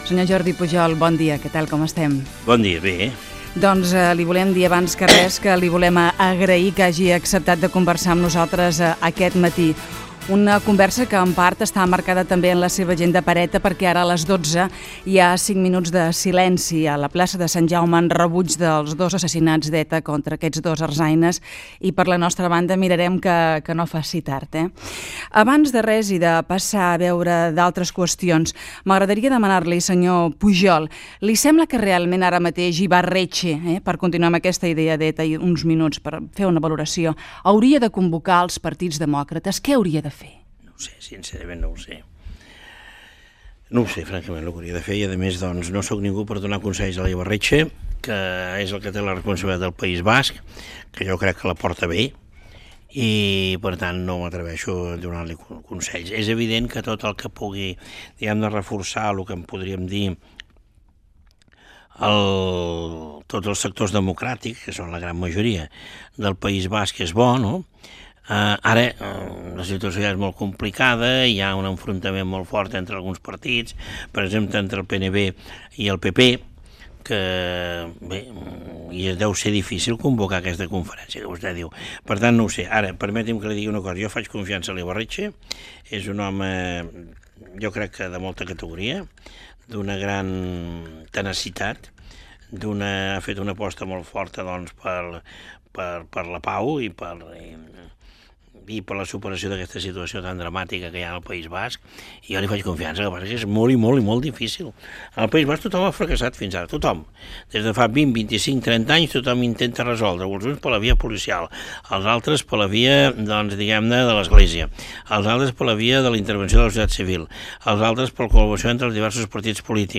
Fragment d'una entrevista al president de la Generalitat Jordi Pujol. Opinions i comentaris sobre ETA i Euskadi.